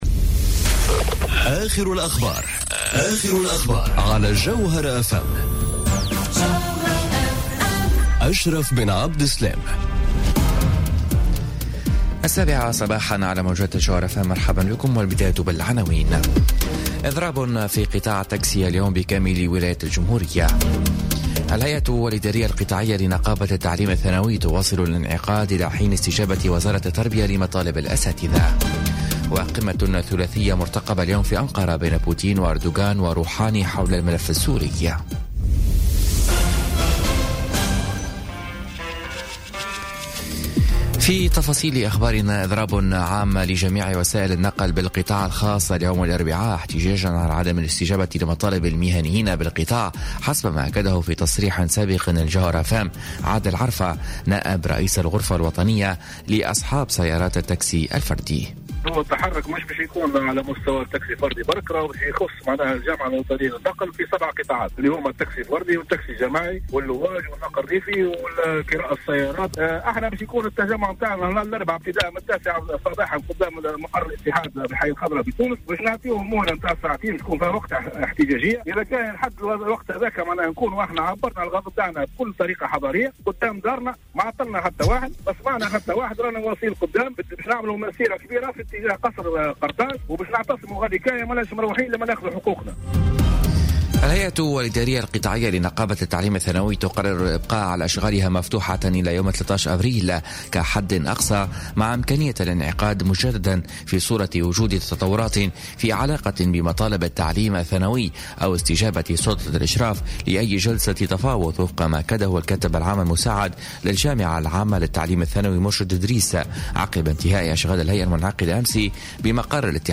نشرة أخبار السابعة صباحا ليوم الإربعاء 4 أفريل 2018